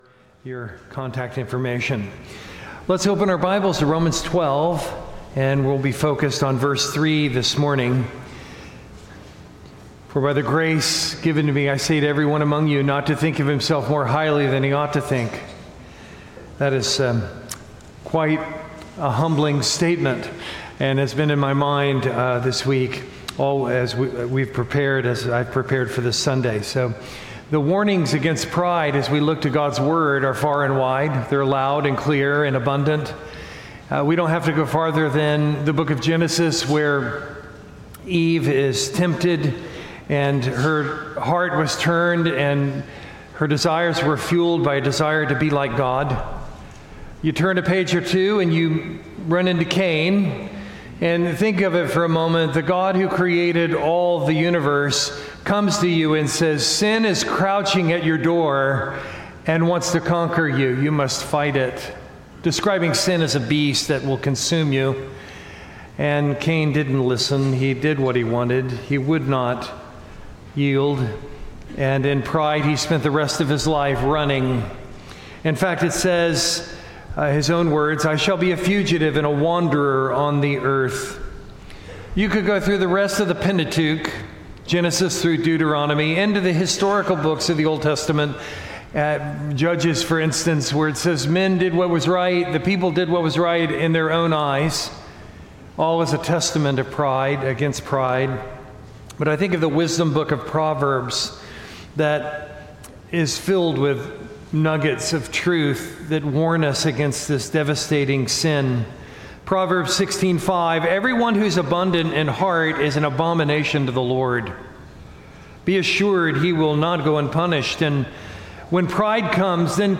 Sermon content from First Baptist Church Gonzales, LA